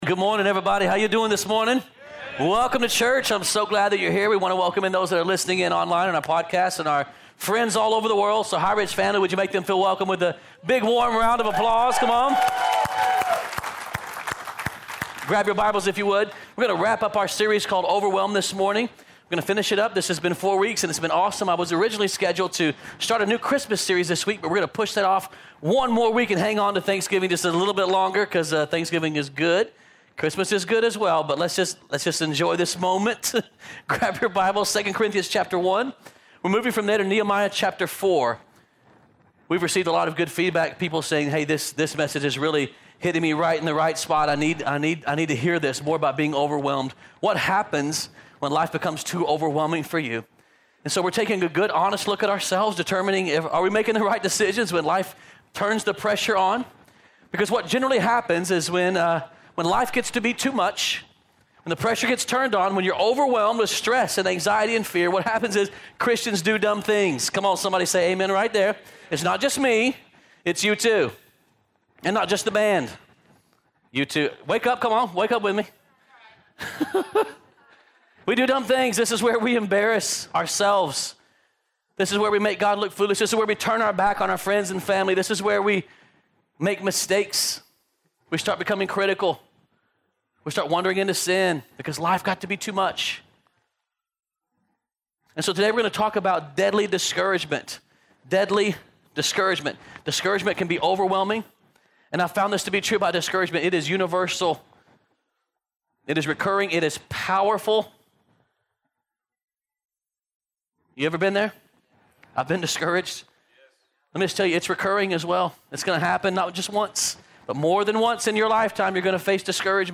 2018 Sermon